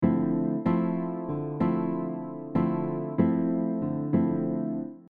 This mellow and beautiful style uses syncopated rhythms with a fingerpicking style of playing.
A typical characteristic in Bossa Nova patterns is that the bass notes land on beats 1 and 3 while the upper notes of the chord change.
Basic bossa nova rhythm over one chord
Bossa-Nova-pattern-1.mp3